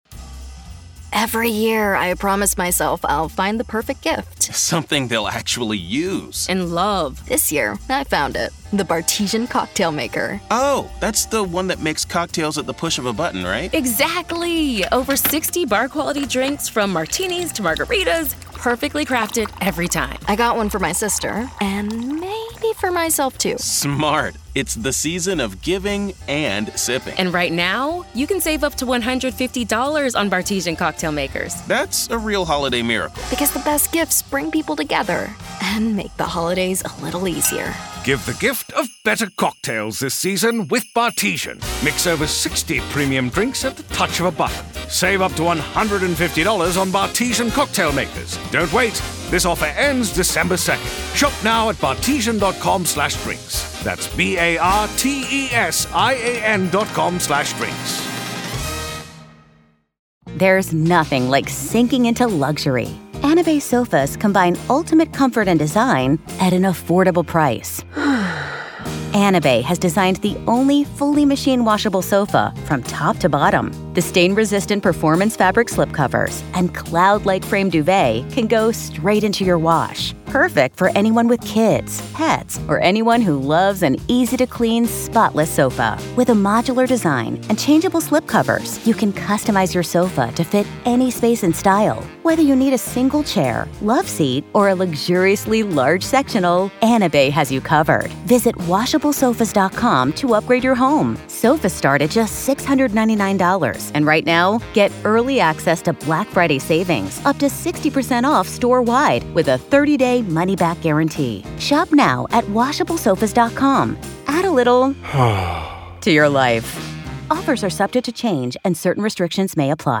The latest Spanish news headlines in English: 1st July 2024